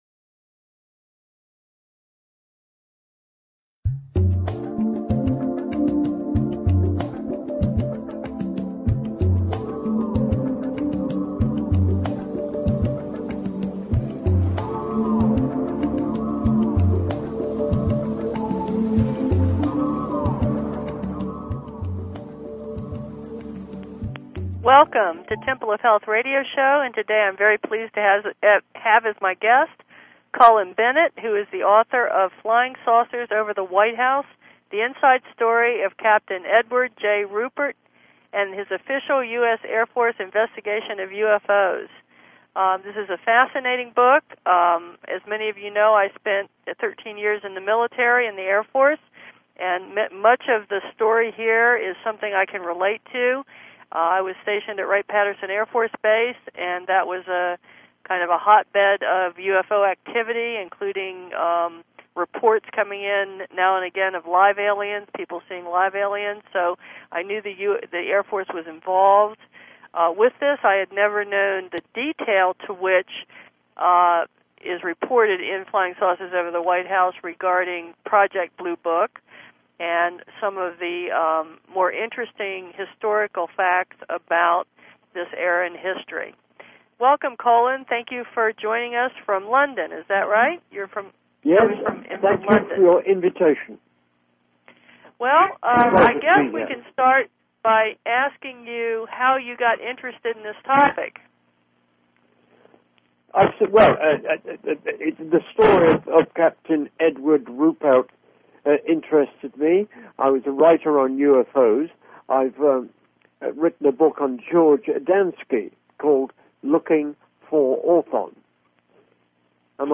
Each week we bring the audience a personalized educational experience with leading authors, national and local experts on the following topics: Traditional and Holistic Medicine Integrative Health Philosophies Spiritual Growth and Fulfillment Scientific Breakthroughs Various Medical Disorders Including: Diabetes, Cancer, Thyroid, Depression, Heart Disease, Arthritis, Alzheimer's, Nutrition, Auto Immune Diseases and Many More.